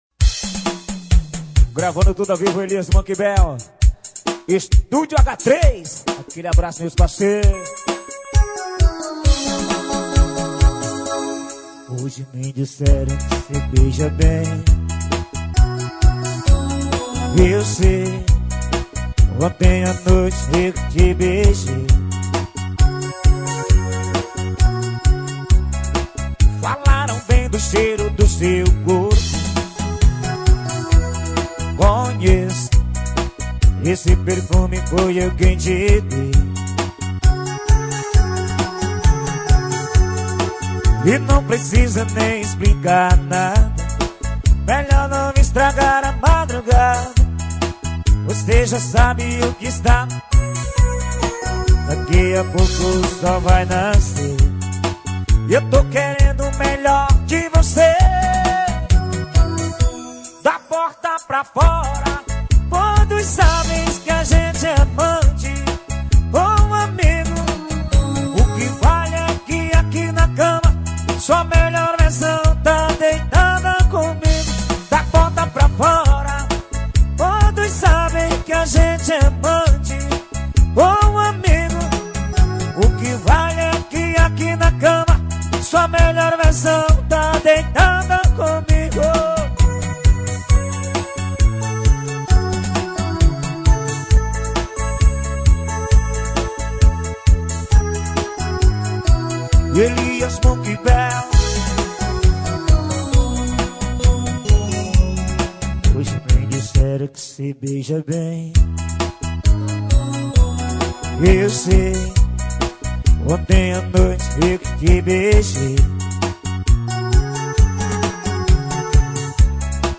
2024-06-17 01:31:25 Gênero: MPB Views